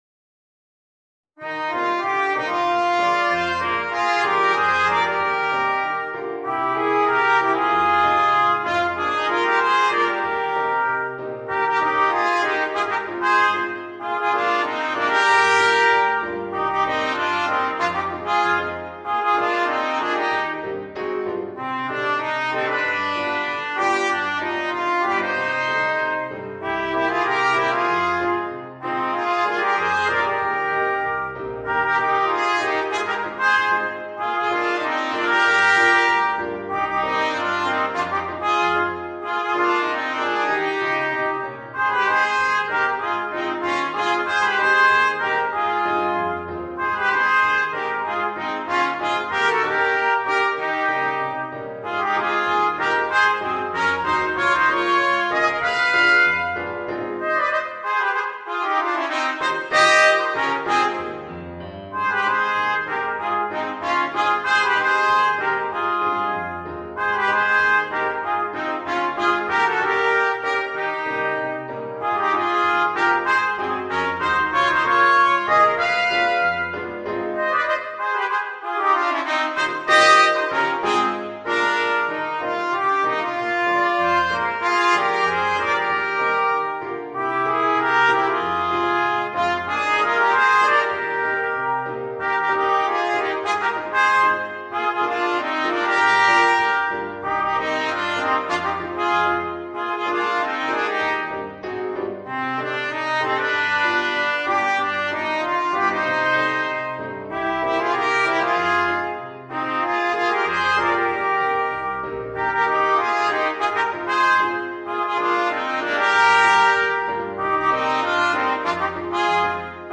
Voicing: 2 Trumpets and Piano